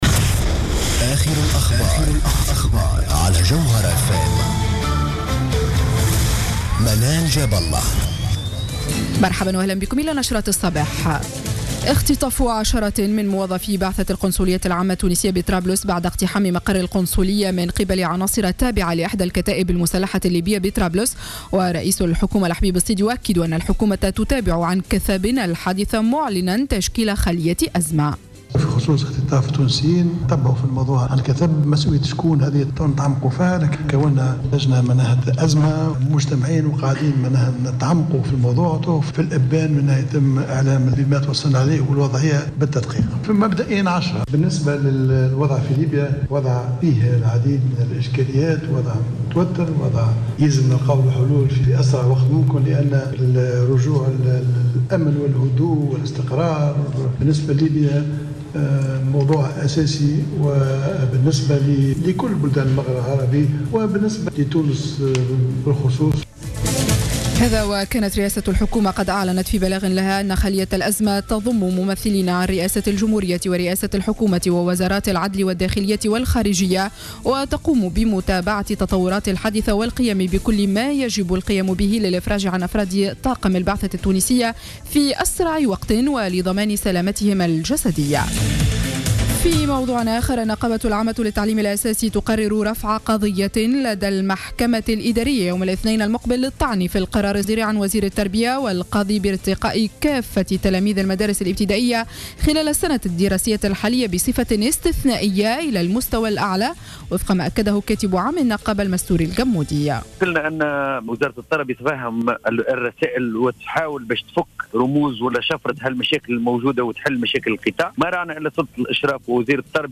نشرة أخبار السابعة صباحا ليوم السبت 13 جوان 2015